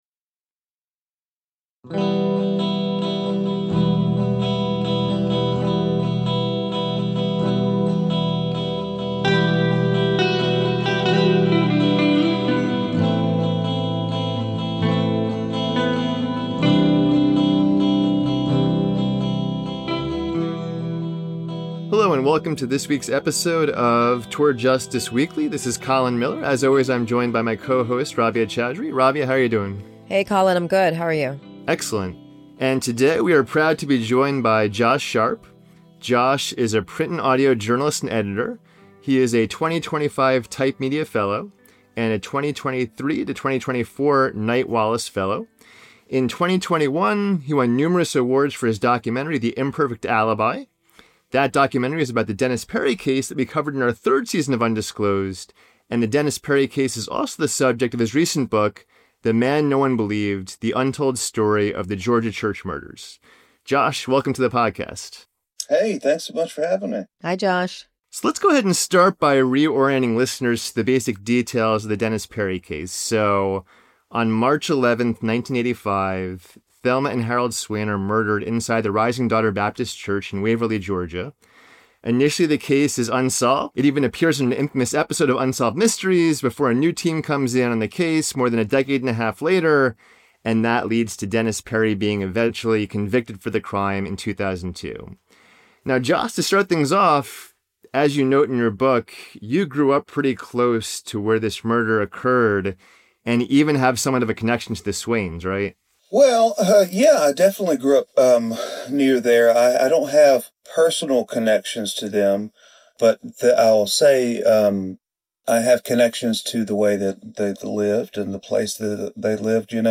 TJ Weekly - Interview